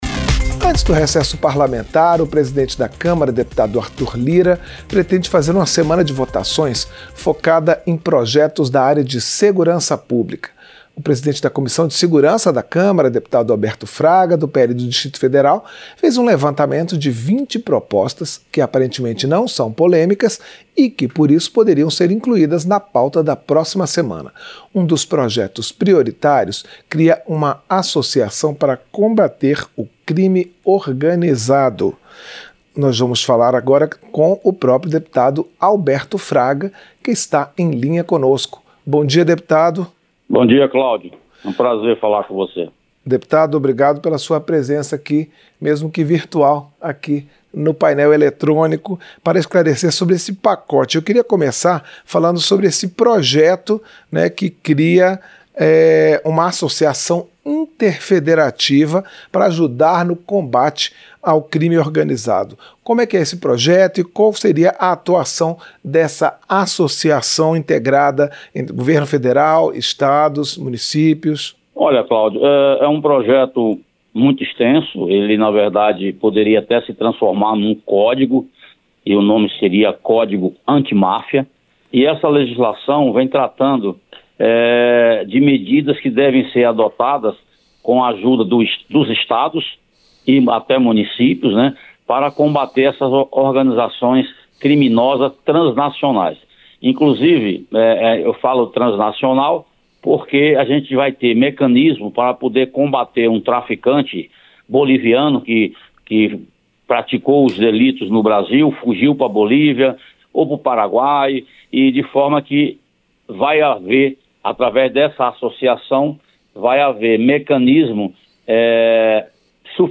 Entrevista - Dep. Alberto Fraga (PL-DF)